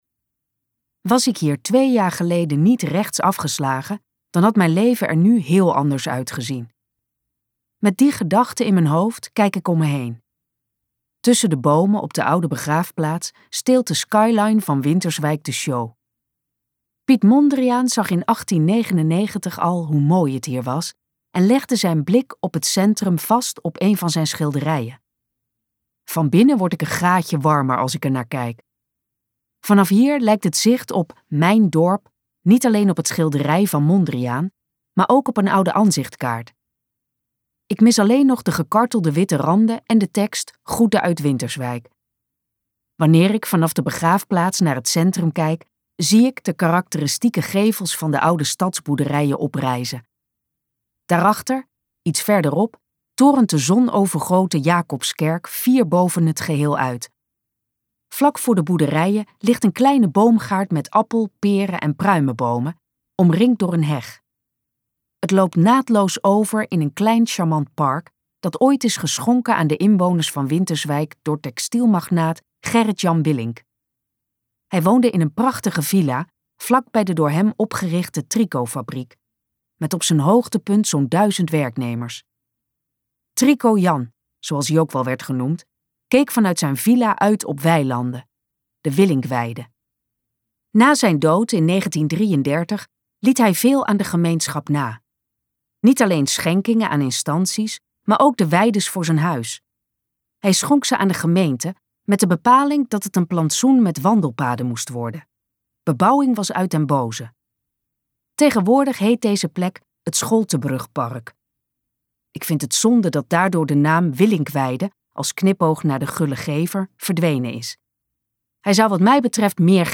De ramp met de phoenix luisterboek | Ambo|Anthos Uitgevers